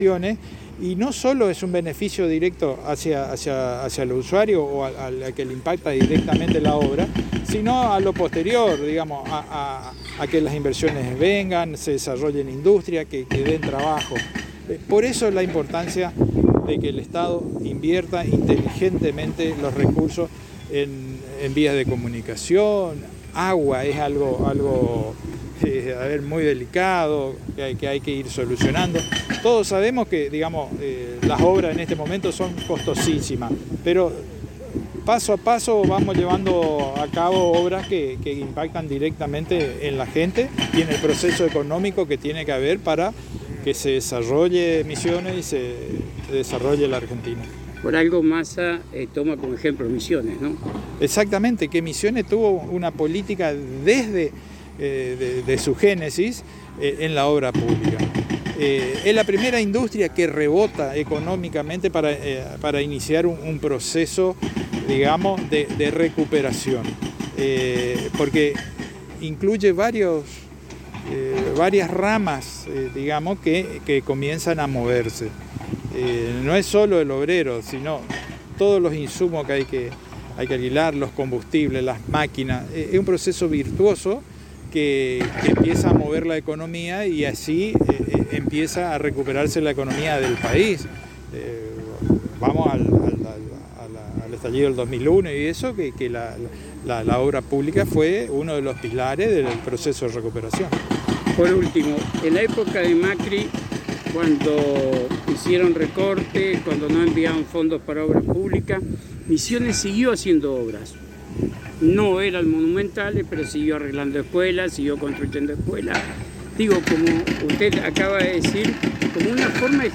El Subsecretario de Obras Públicas de Misiones Marcelo Bacigalupi en diálogo exclusivo con la ANG manifestó que sin obras públicas es imposible mejorar la calidad de vida de los misioneros citando como ejemplo la torre de agua construida por pedido de la Cooperativas de Obras y Servicios Públicos Apóstoles Limitada COSPAL, ya que sin el […]